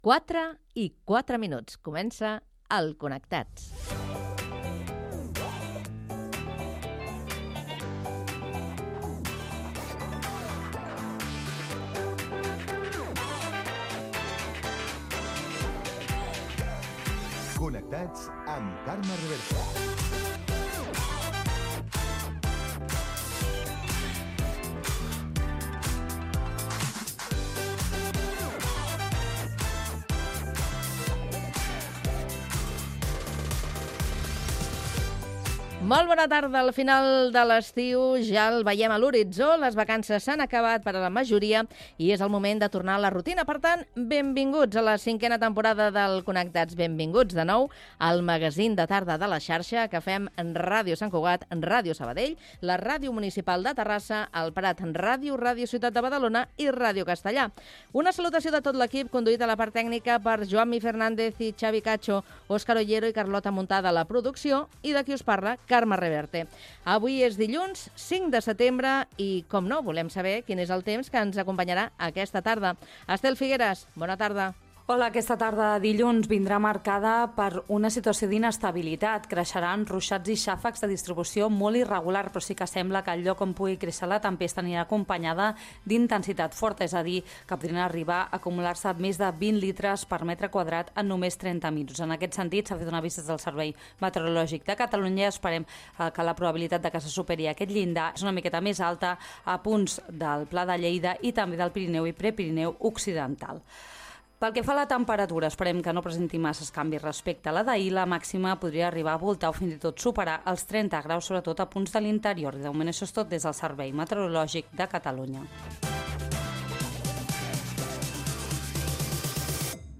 Hora, careta i inici de la cinquena temporada del programa, amb les emissores connectades, equip. Informació meteorològica, sumari de continguts i indicatiu
Entreteniment